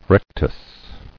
[rec·tus]